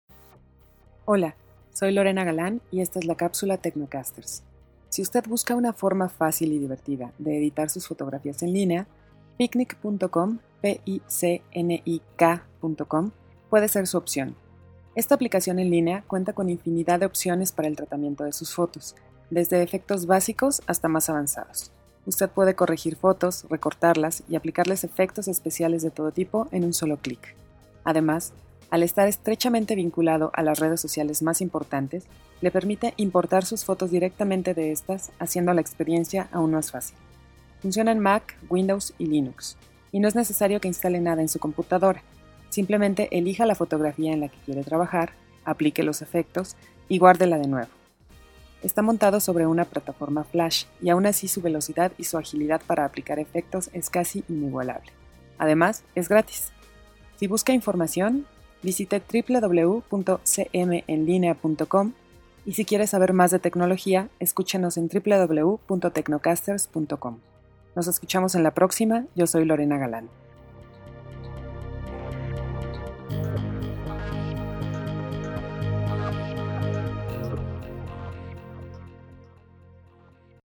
Como editar fotos en linea de manera Gratuita - Capsula para Transmision en Radio